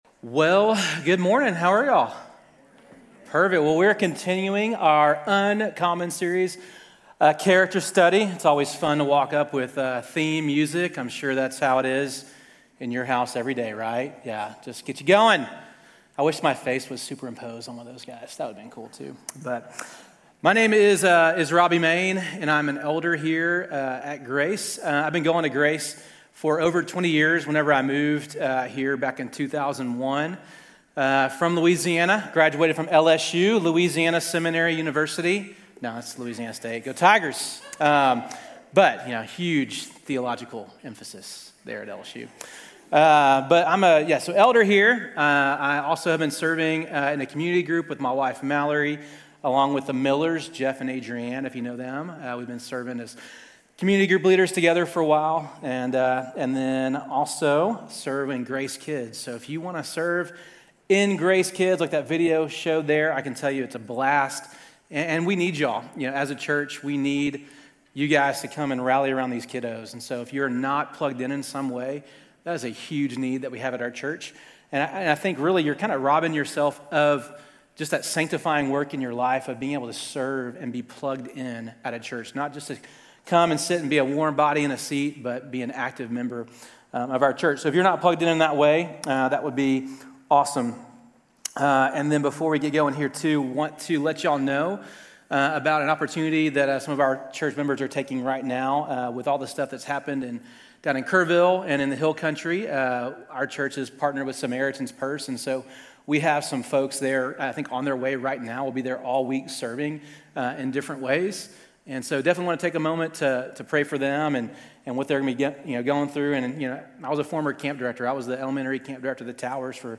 Grace Community Church University Blvd Campus Sermons 7_13 University Bvld Campus Jul 14 2025 | 00:34:38 1x 00:00 / 00:34:38 Subscribe Share RSS Feed Share Link Embed